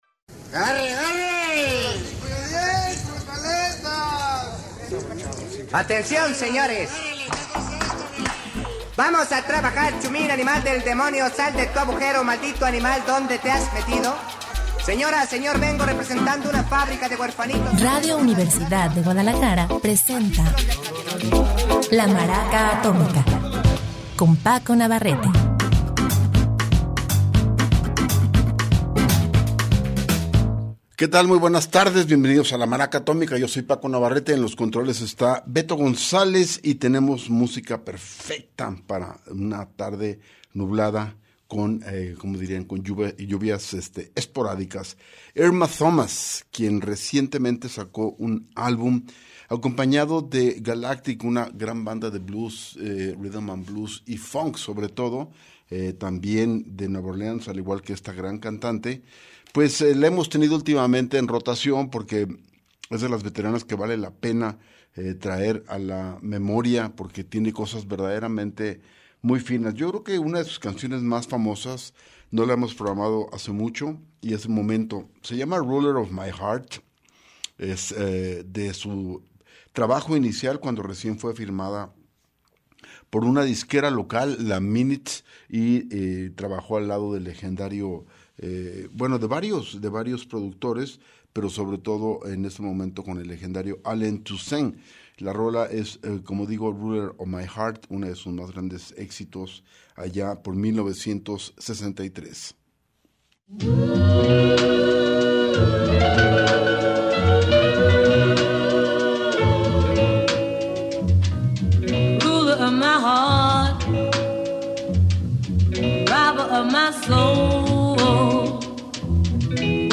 La Maraca Atómica - Lu. 23 Jun 2025 - Musica perfecta para una tarde nublada